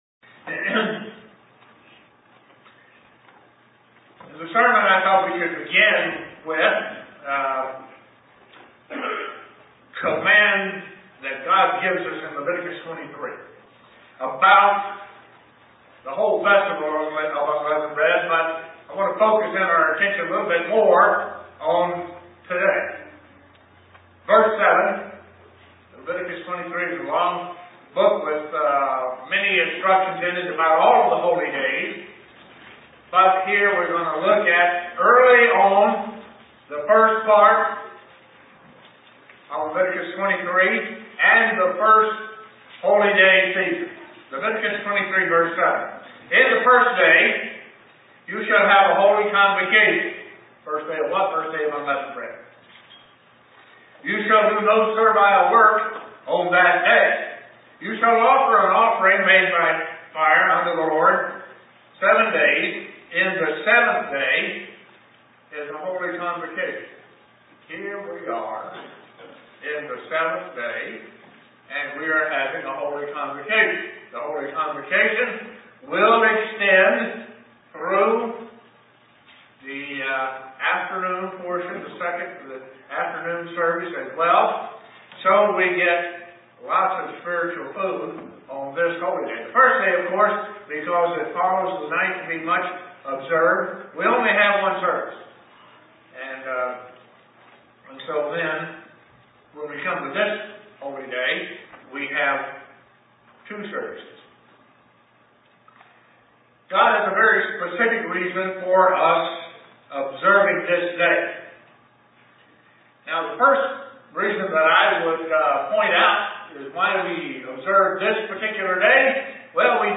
Print Meaning of the Seventh Day of Unleavened Bread UCG Sermon Studying the bible?